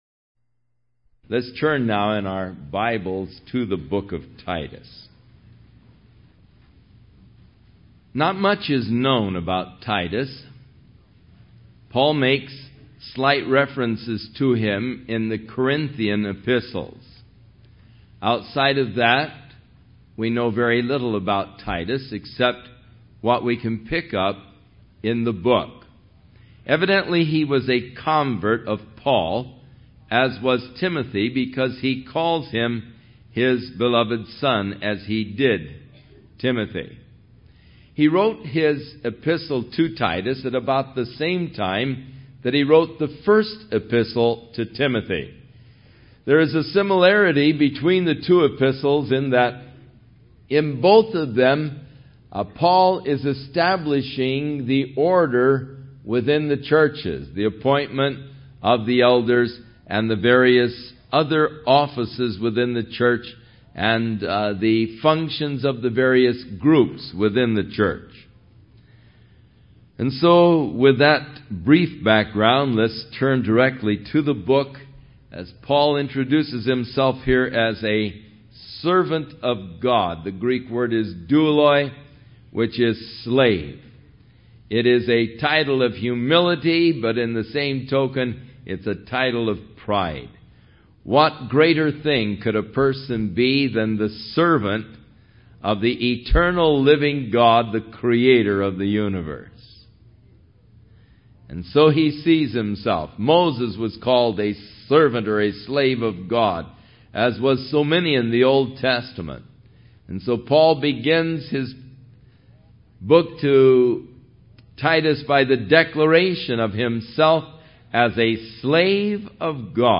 Home / 01 Verse by Verse Teaching / Chuck Smith / Book 56 Titus